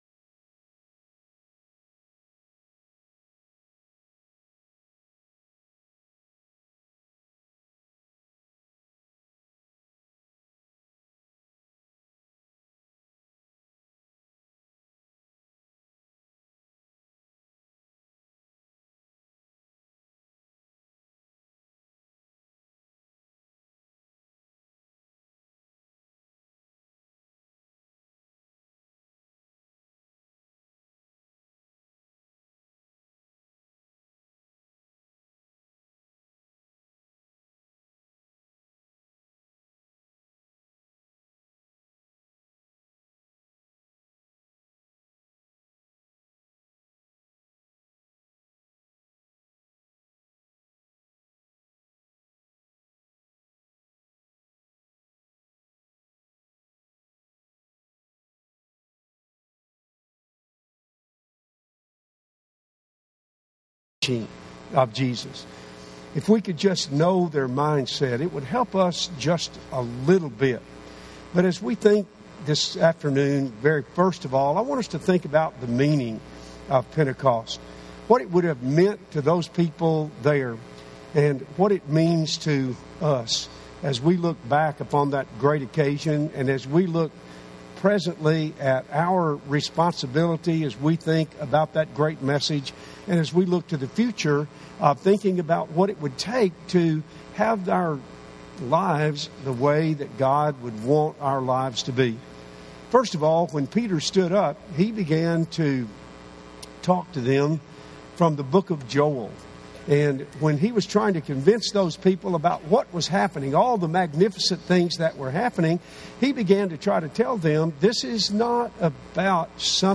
Conviction and Commitment – Henderson, TN Church of Christ